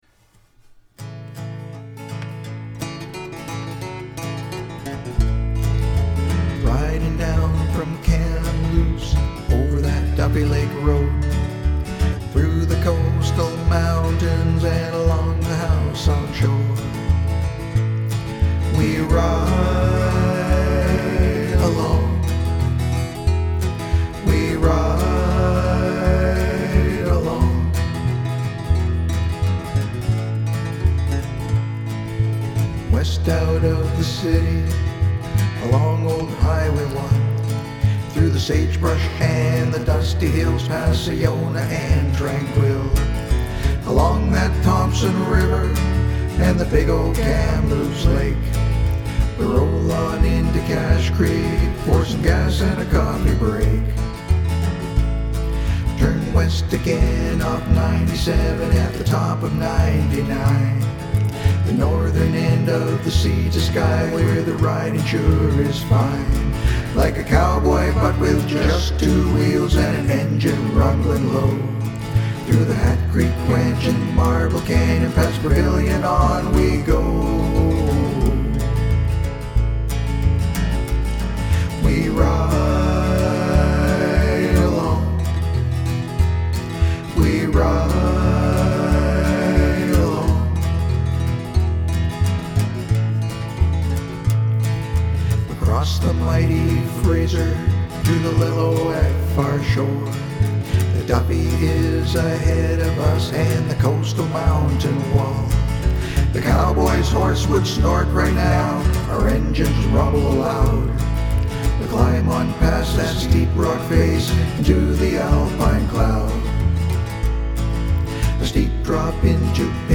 All vocals and instrumentation is by me.